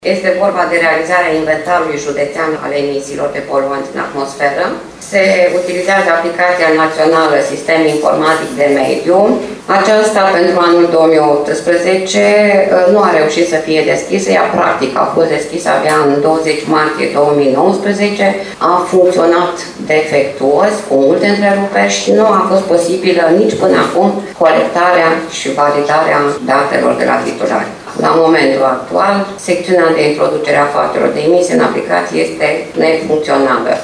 Prefectul județului Suceava MIRELA ADOMNICĂI a prezentat astăzi, în cadrul ședinței Colegiului Prefectural, planul de acțiuni pentru îndeplinirea programului de guvernare în primul semestru al anului.